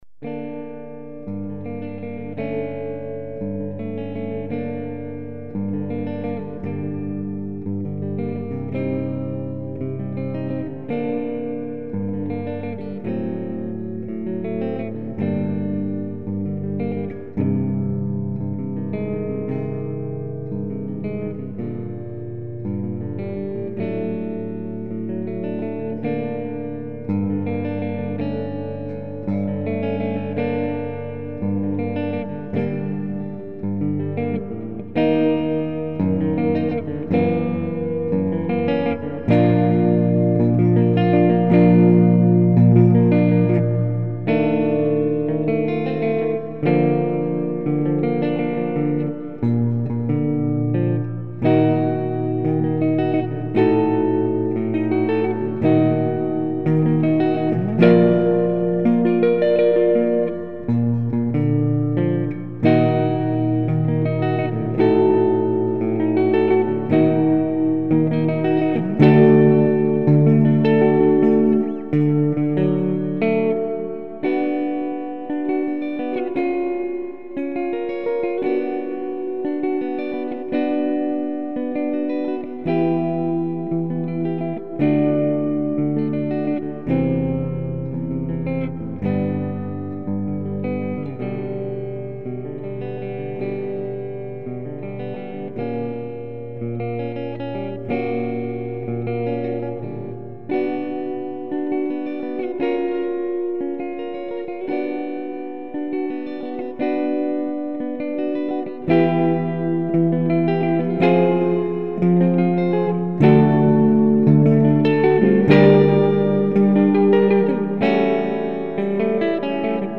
・下の方にあるのは、僕が作った簡単なギター編曲版です。
超絶技巧練習曲集 第６番　“幻影”　ギター簡易編曲版
vision_guitar.mp3